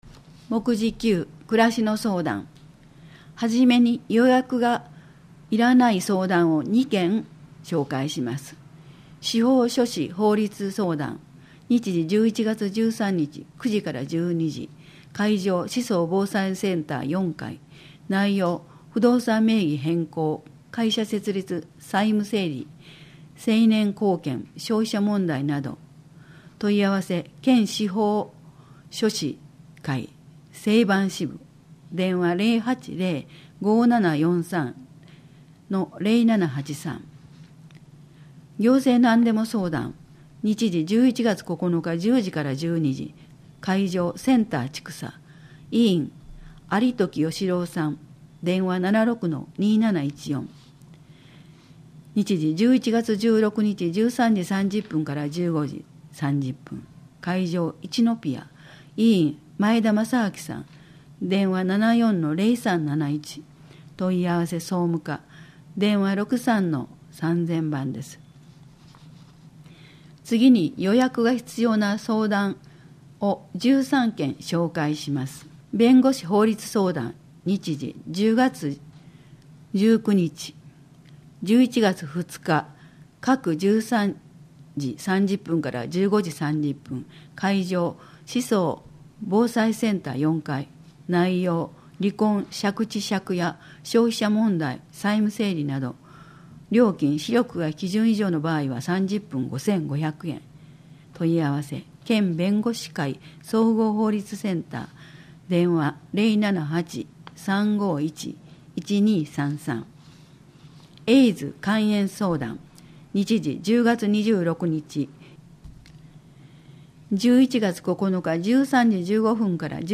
このページでは、広報しそうの記事が録音された「声の広報」が楽しめます。